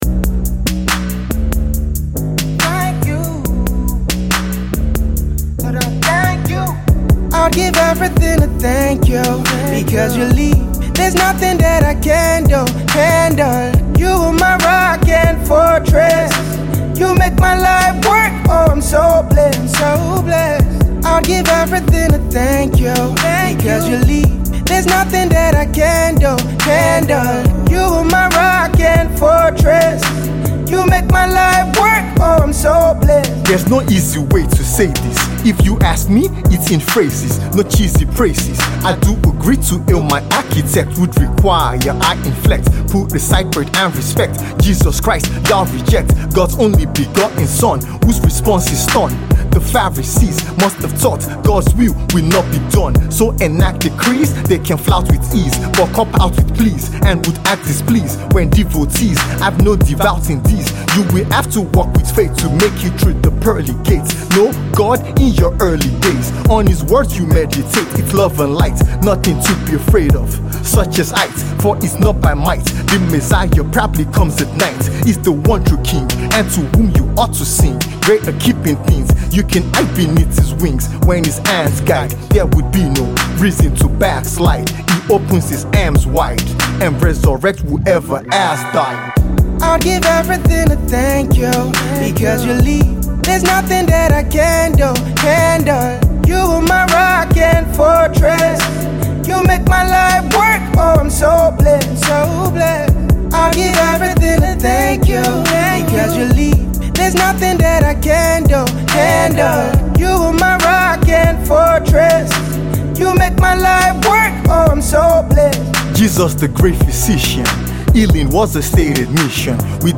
captivating and soul-stirring Christian worship song
contemporary Christian Hip-Hop record